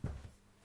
krok_08.wav